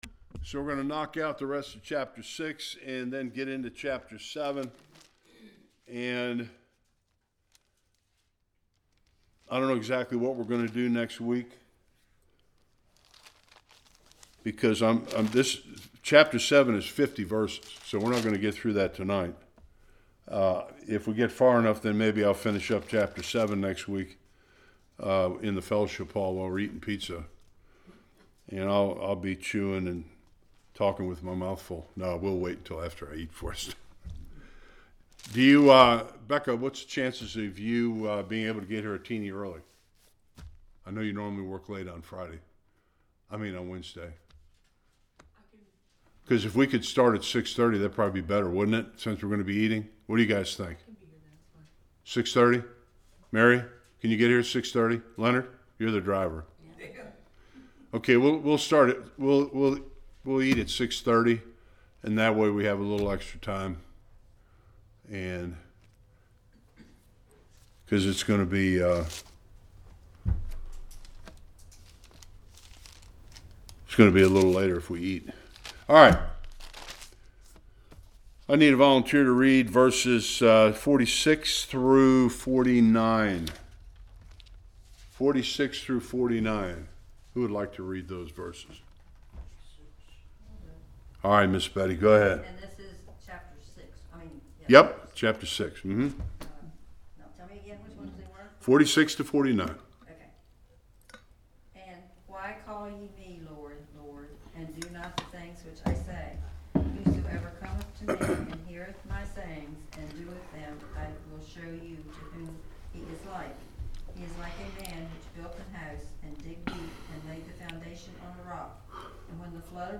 1-17 Service Type: Bible Study Chapter 6 ends with Christ describing building a life of obedience to God.